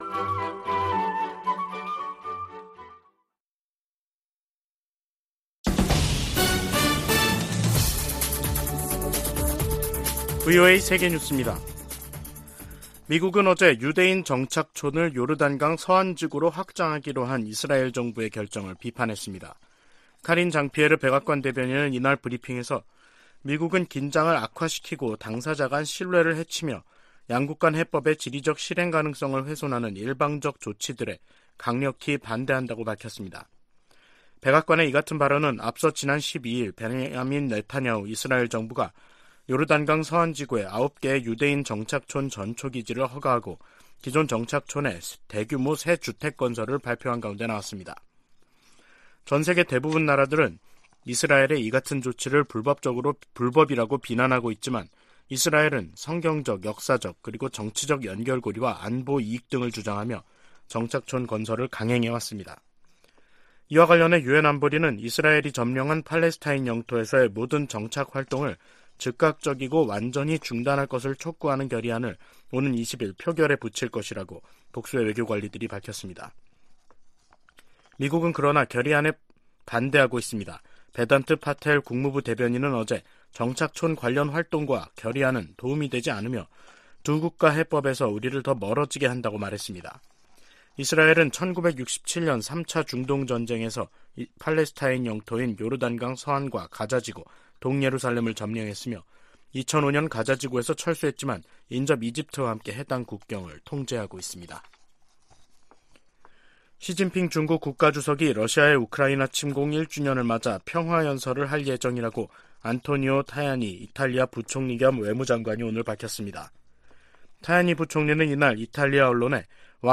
VOA 한국어 간판 뉴스 프로그램 '뉴스 투데이', 2023년 2월 17일 2부 방송입니다. 북한의 플루토늄 20kg 증가는 원자로 지속 가동의 결과이며, 올해 6kg 추가가 가능하다고 전 IAEA 사무차장이 밝혔습니다. 러시아 용병 회사 바그너 그룹을 즉각 테러 단체로 지정할 것을 촉구하는 법안이 미 상원에 초당적으로 발의됐습니다. 미국과 한국이 다음달 중순 대규모 야외기동훈련을 포함한 연합훈련을 실시합니다.